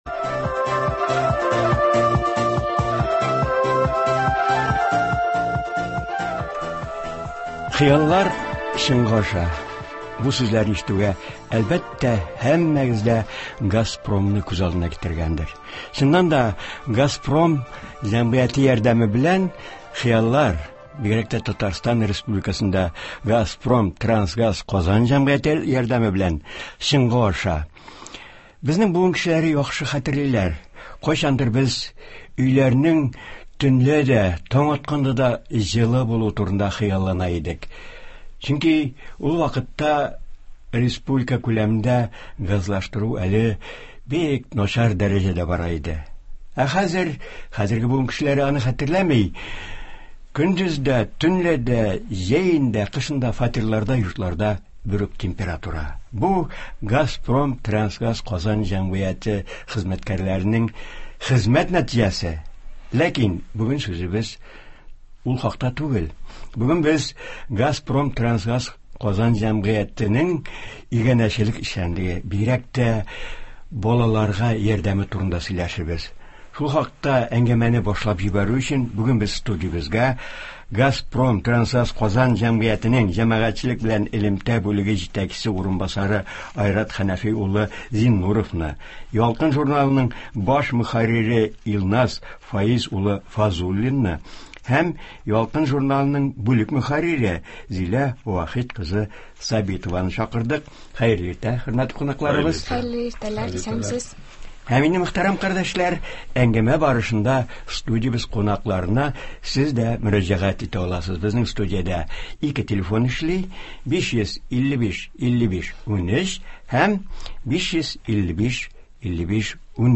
Туры эфир (09.11.20)